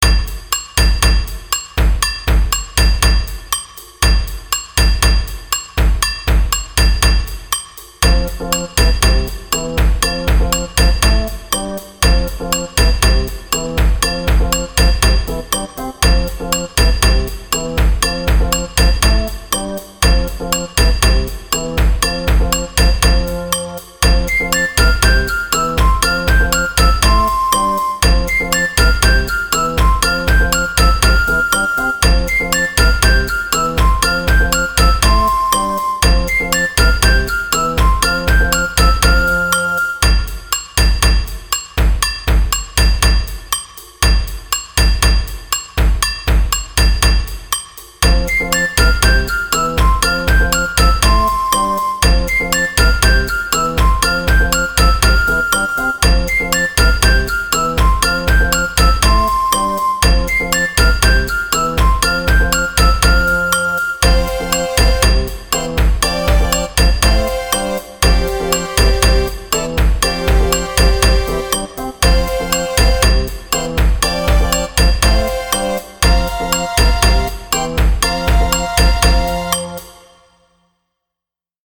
～～不思議な曲～～